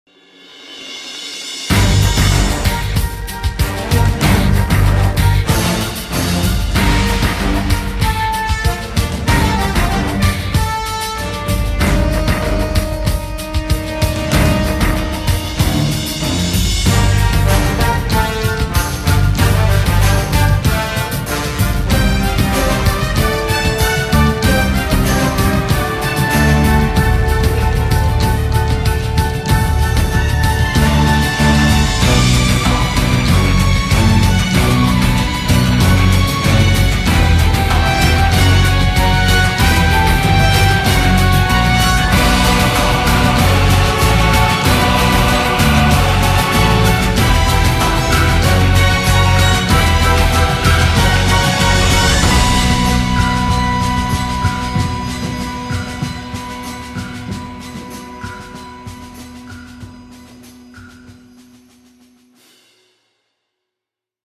Sounds of the game World of tanks, download and listen online
• Quality: High